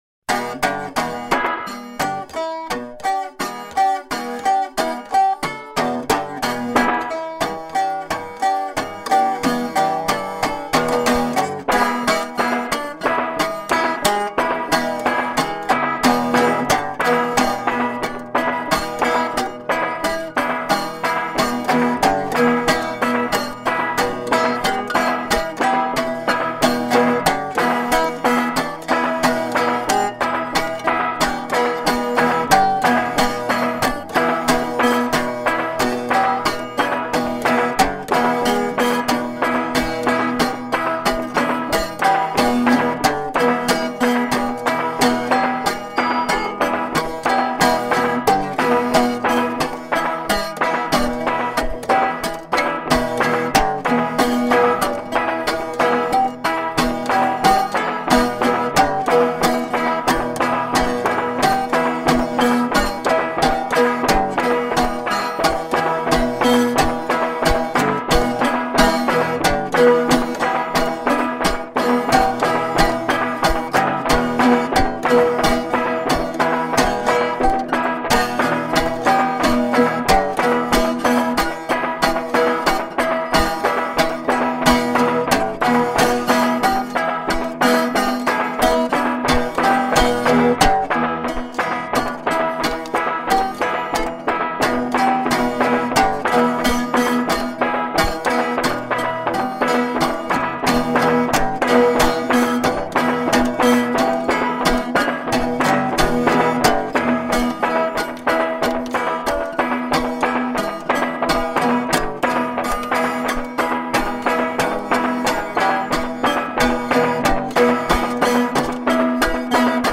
Genre: Freie Musik - Blues
Info: Gitarrenblues